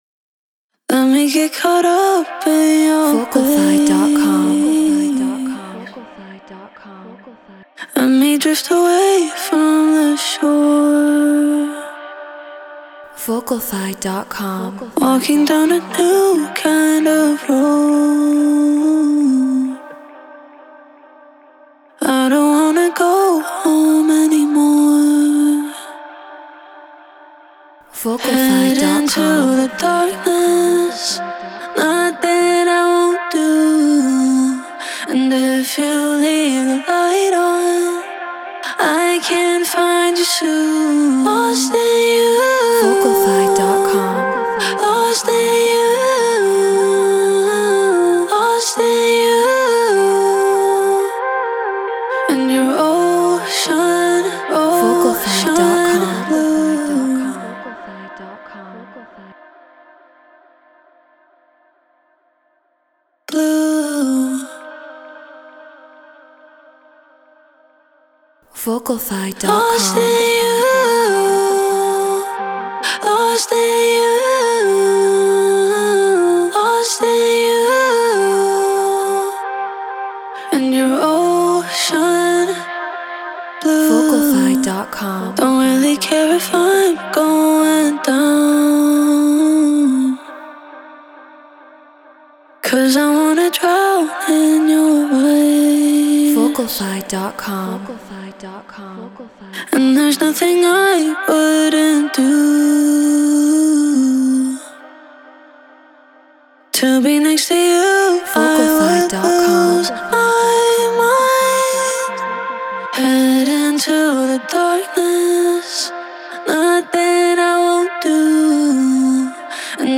Garage 136 BPM Cmin
Shure SM7B Apollo Solo Logic Pro Treated Room